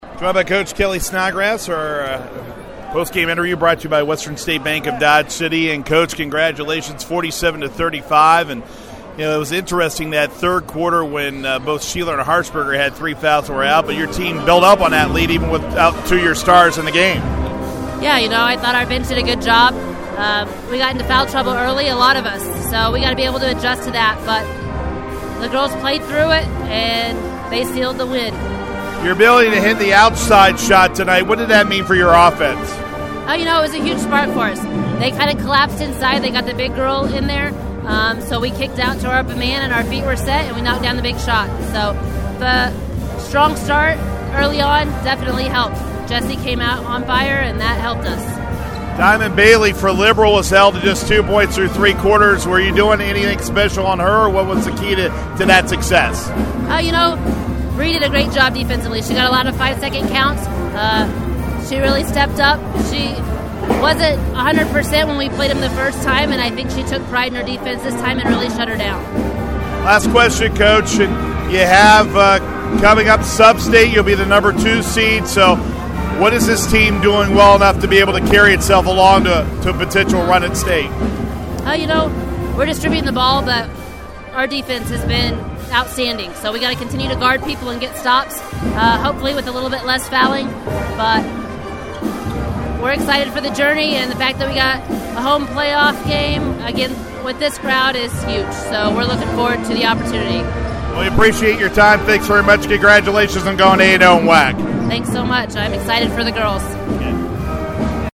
Post-game comments